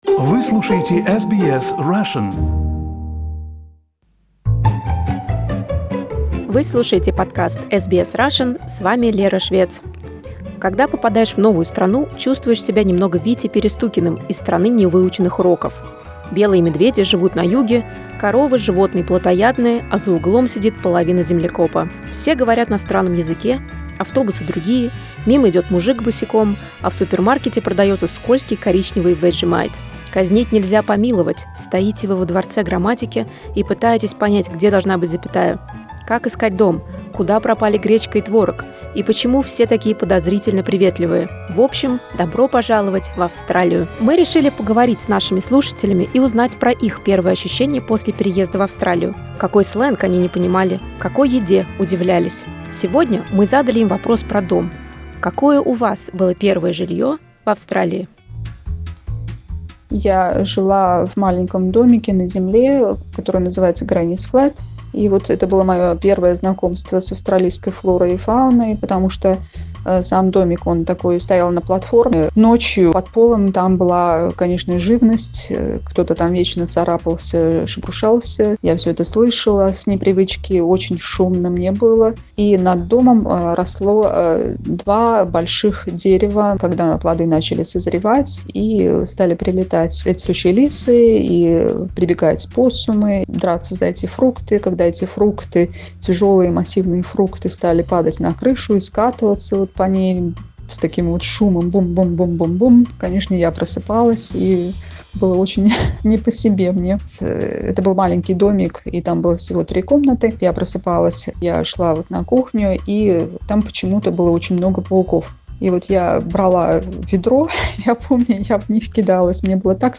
Vox-pop: Tell about your first house in Australia
From the hustle and bustle of a club district to fighting possums and termites — our listeners shared their stories of their first houses in Australia.